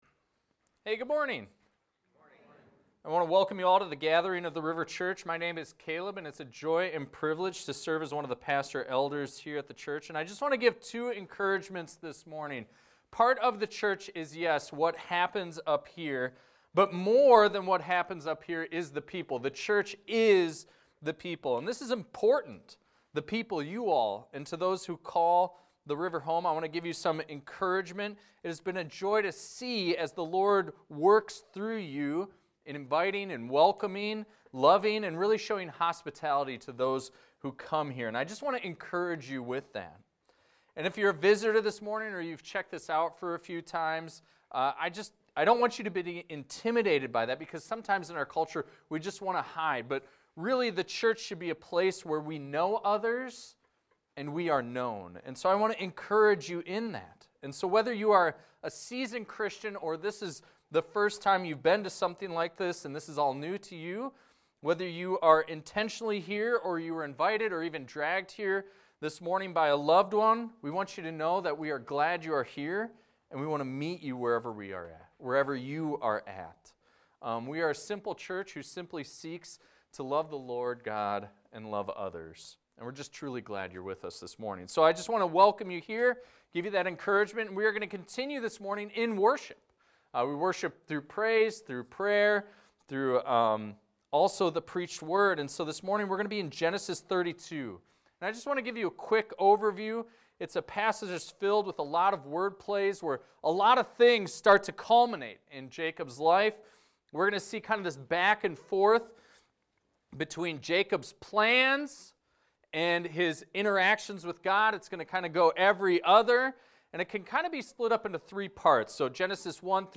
This is a recording of a sermon titled, "Wrestling with God."